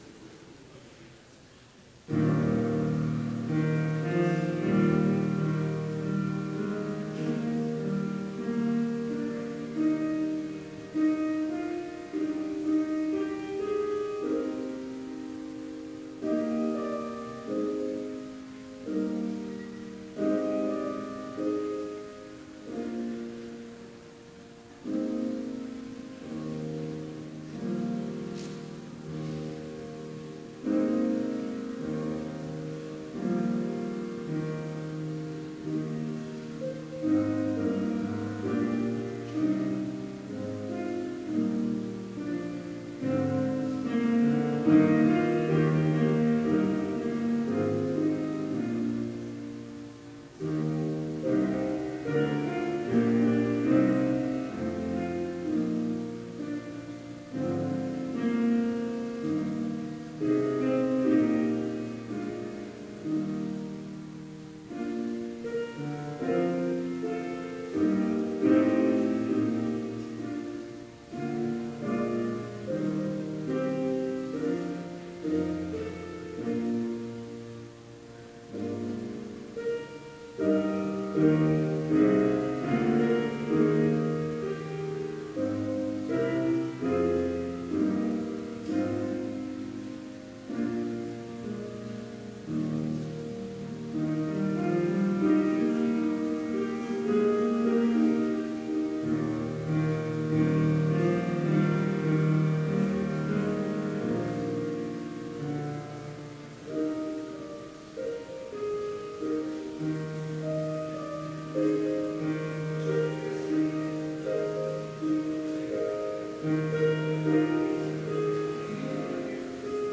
Complete service audio for Chapel - May 10, 2018